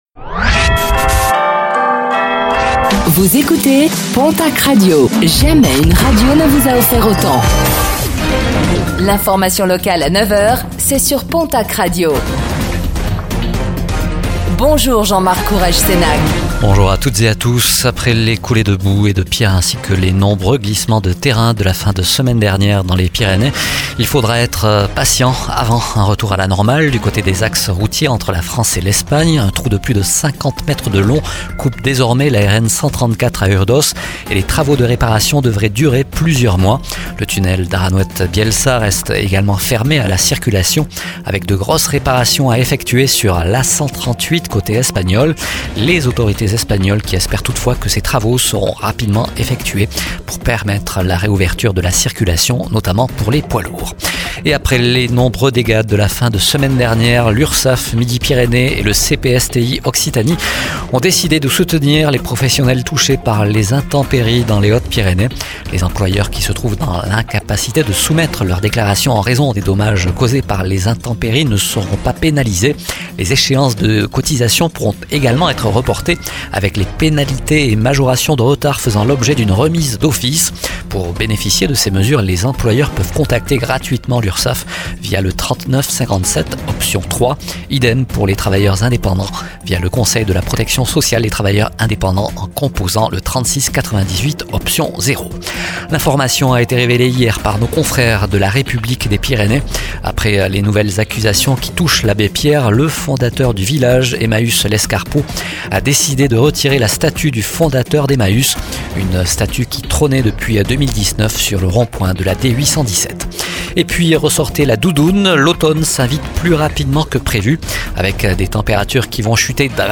Réécoutez le flash d'information locale de ce mardi 10 septembre 2024